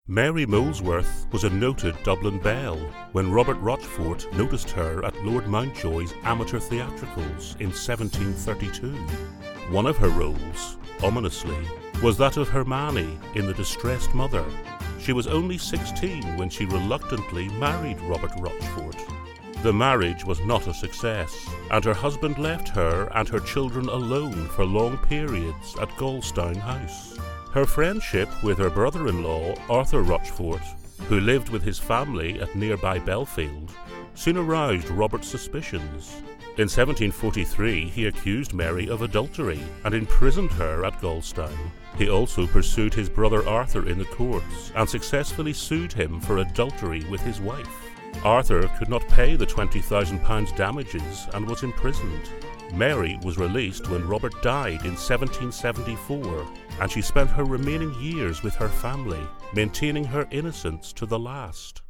Documentaries
I have a soft engaging Northern Irish Accent
Baritone , Masculine , Versatile . Commercial to Corporate , Conversational to Announcer . I have a deep, versatile, powerful voice, My voice can be thoughtful , authoritative , storytelling and funny . Confident and able to deliver with energy , humour and conviction .
RODE NT1A . TECPORTPRO , ISOLATED SOUND TREATED BOOTH. EDIT WITH AUDACITY